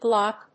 /glάp(米国英語), glˈɔp(英国英語)/
glop.mp3